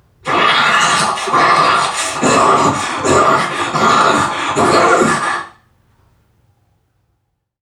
NPC_Creatures_Vocalisations_Robothead [71].wav